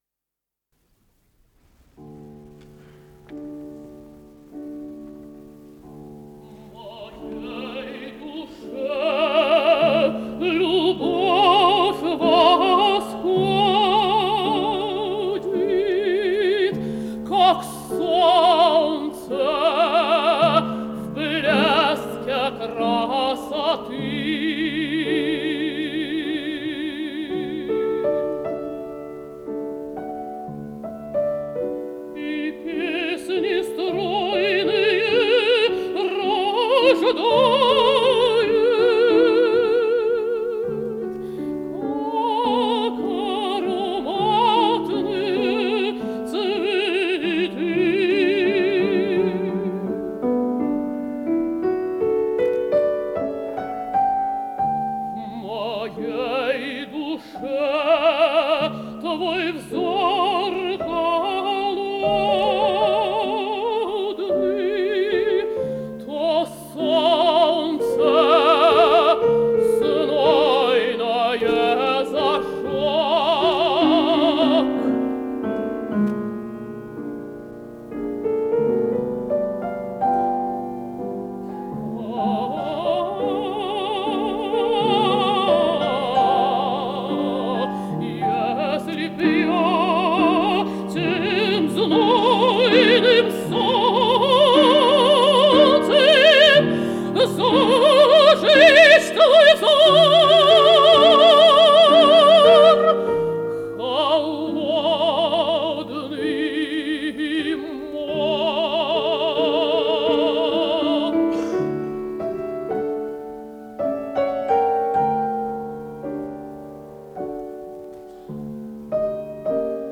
ИсполнителиЕлена Образцова - пение
фортепиано